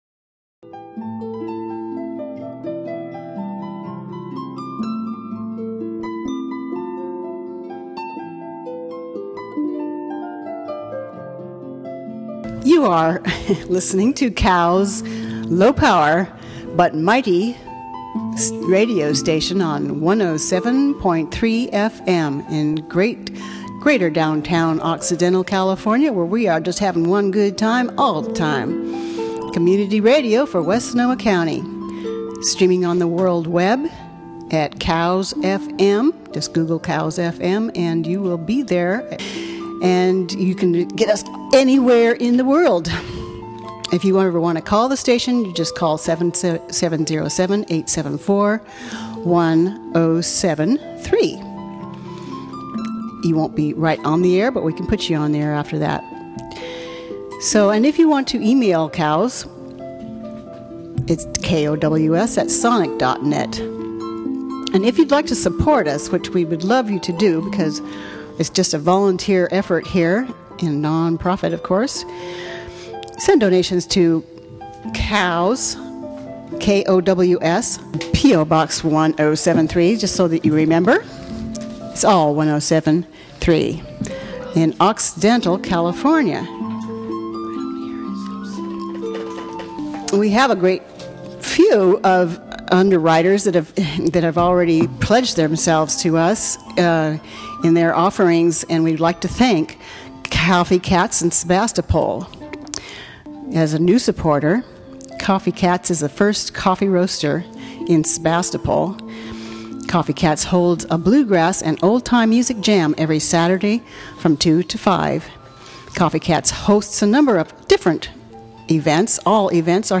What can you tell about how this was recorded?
Redwood Muse Radio Live radio presentation of Alice in Wonderland, James & the Giant Peach and Willy Wonka.